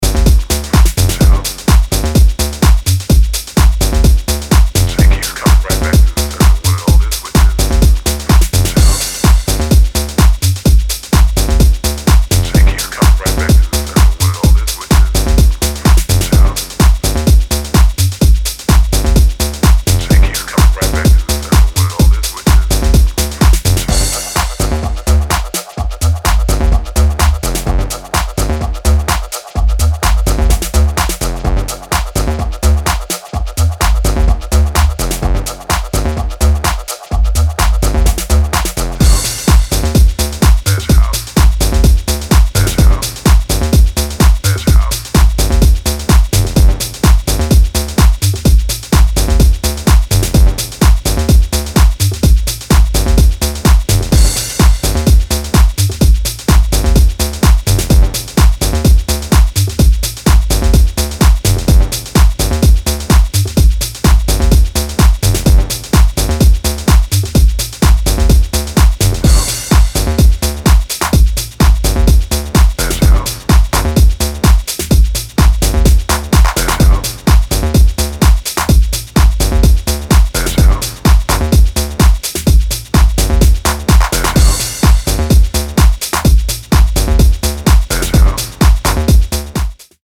ソリッドなハット&スネアにダーティーなベースラインがこの世代のシカゴ・ハウスを思わせる